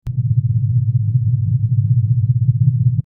Alien Spaceship Hum
Alien_spaceship_hum.mp3